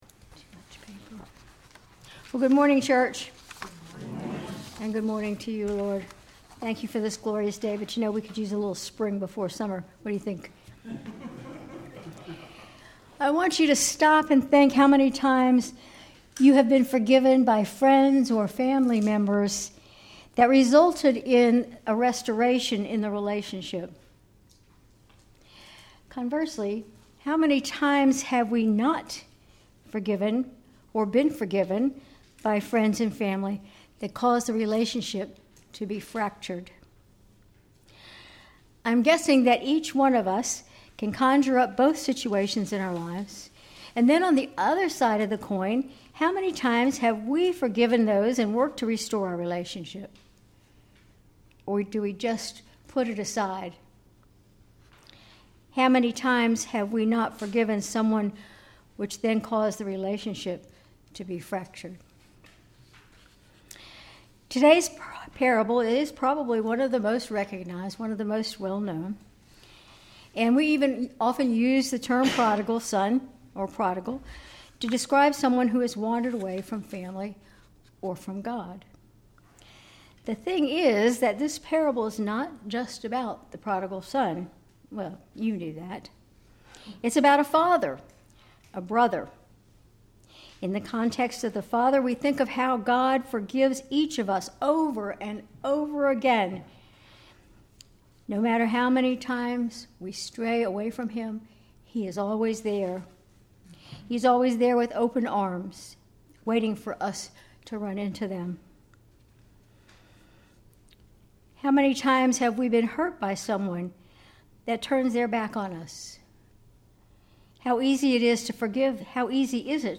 Sermon March 30, 2025